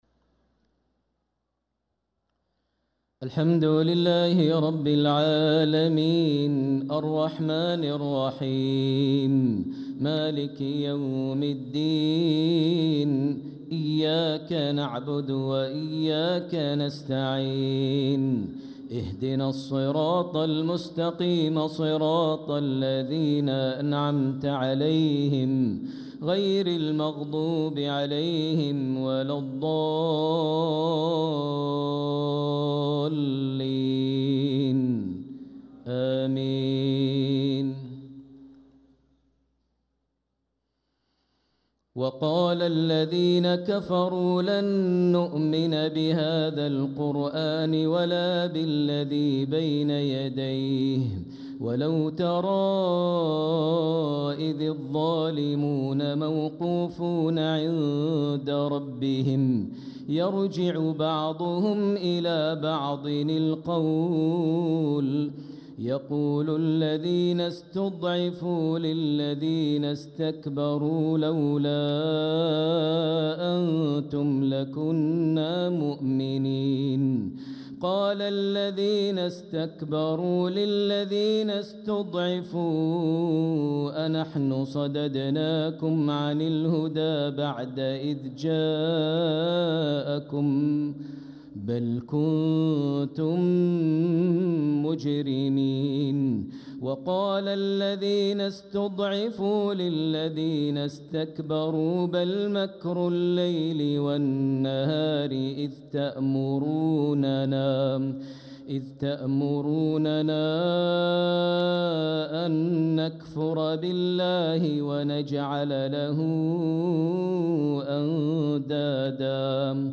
صلاة العشاء للقارئ ماهر المعيقلي 21 رجب 1446 هـ